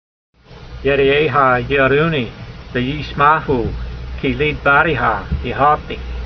Sound (Psalm 119:74) Transliteration: ye r(d)ay'ey'ha yeer(d) oo nee ve'yees' ma hoo , kee leedvar(d)e ha yee hal tee Vocabulary Guide: Those who fear you will see me and rejoice : For I have hoped in your word .
v74_voice.mp3